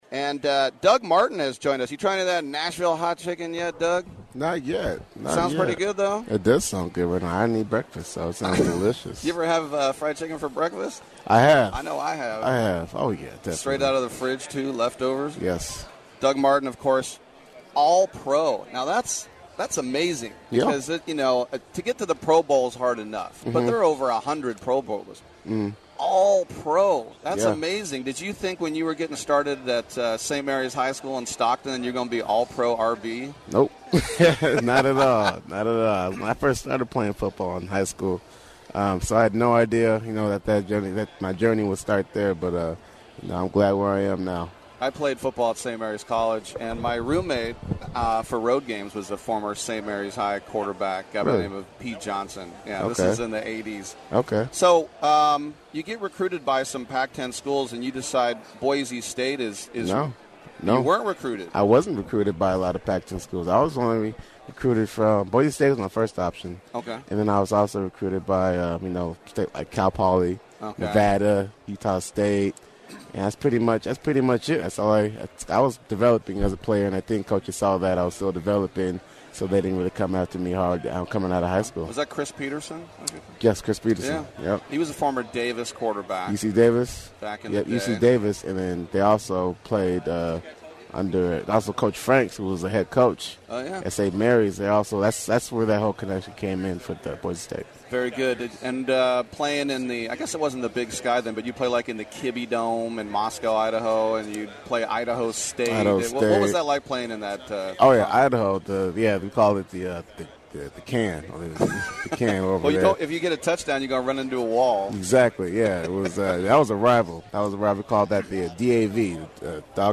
Live From Super Bowl L Radio Row: The Late Doug Martin
We at Sports Byline were saddened to hear of the death of former All-Pro running back Doug Martin over the weekend. We wanted to share our interview with him at Super Bowl L Radio in San Francisco in 2016.